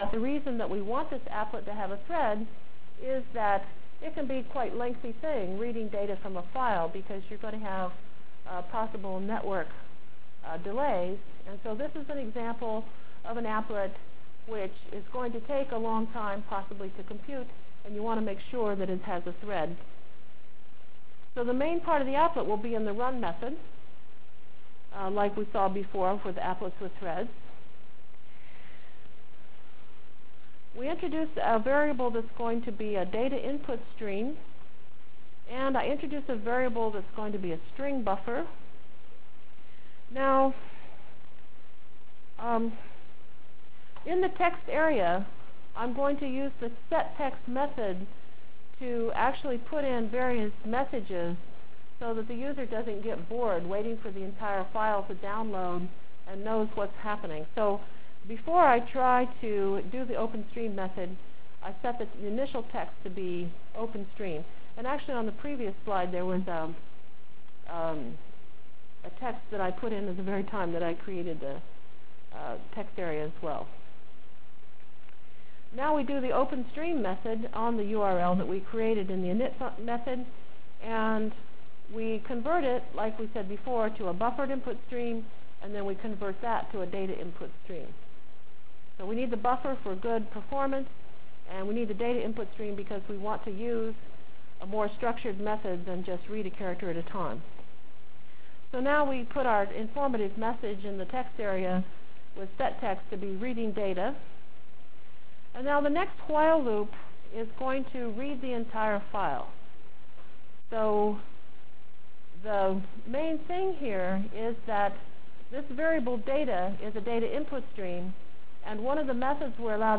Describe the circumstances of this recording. From Feb 3 Delivered Lecture for Course CPS616 -- Java Lecture 4 -- AWT Through I/O CPS616 spring 1997 -- Feb 3 1997.